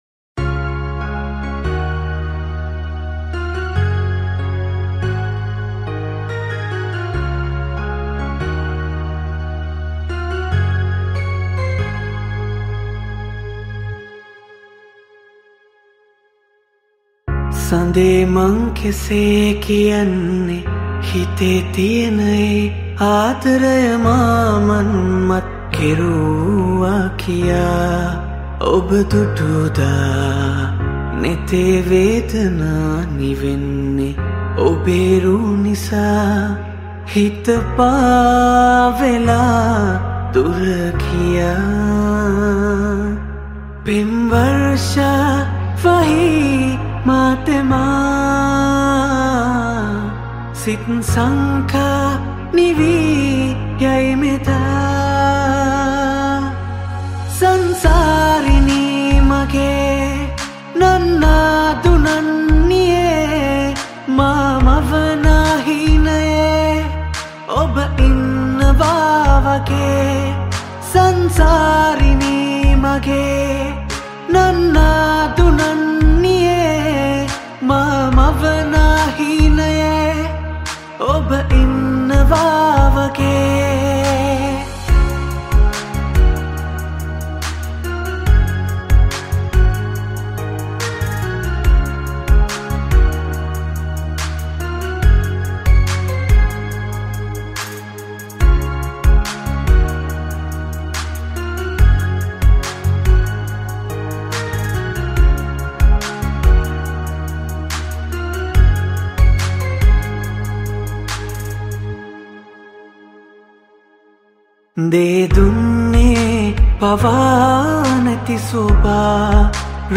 Banjo
Flute
Bass
Additional Vocals